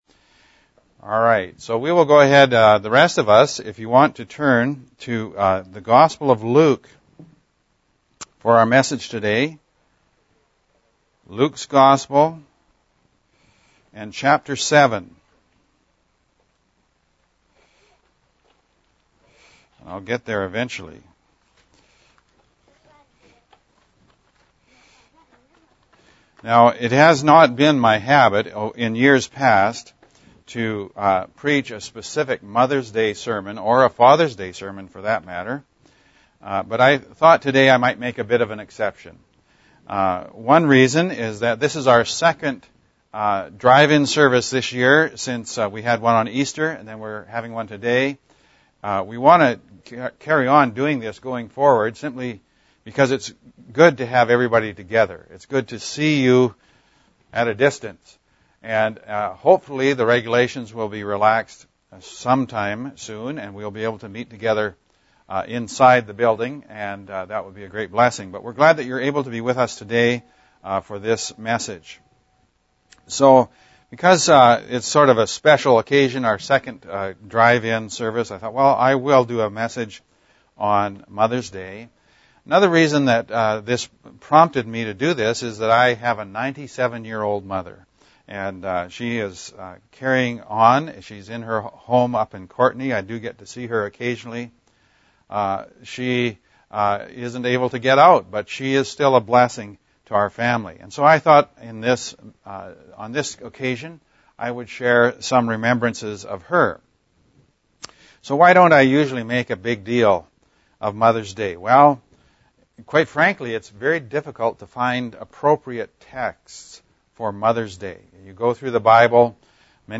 Lk 7.31-35 We held a Drive-In service today, for Mother’s Day.